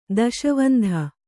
♪ daśavandha